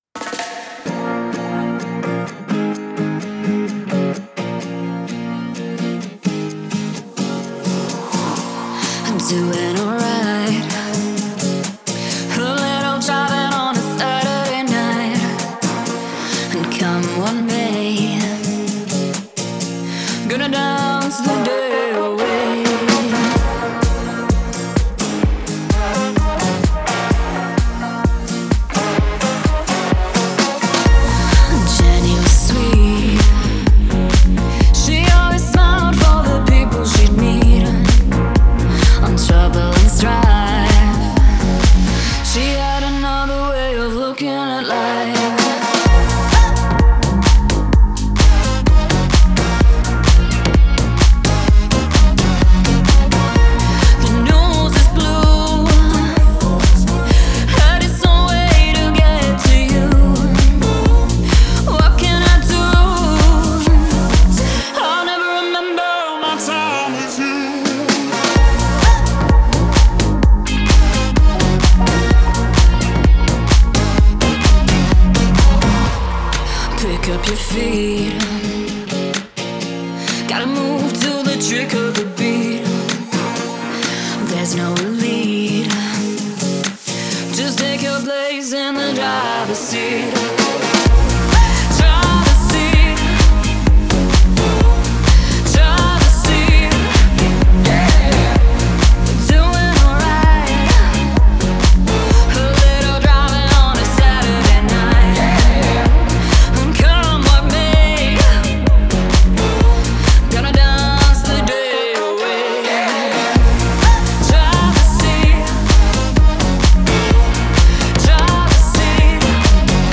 это захватывающая композиция в жанре электропоп